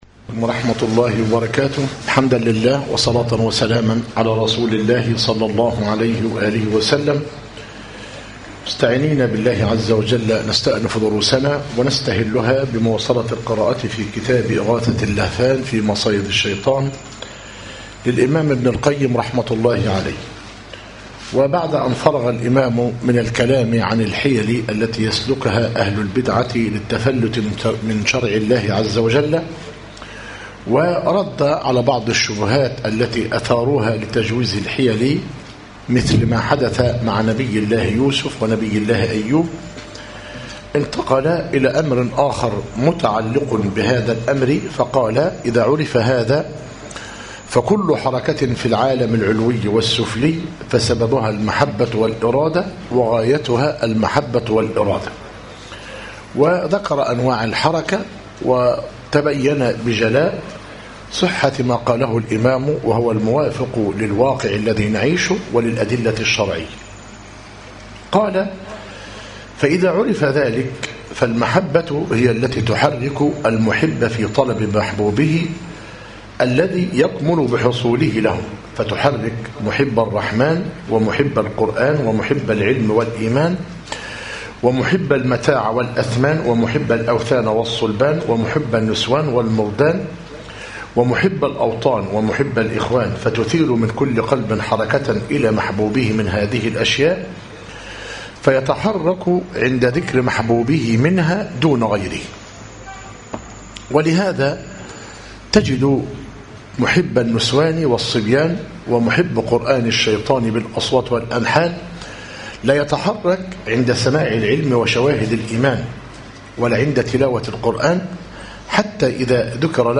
إغاثة اللهفان من مصائد الشيطان - مسجد الرحمة آل خلف - ميت غمر - الدقهلية - المحاضرة الرابعة والسبعون - يوم الاثنين من بعد الظهر - بتاريخ 30 - ذو القعدة - 1436هـ الموافق 14- سبتمبر- 2015 م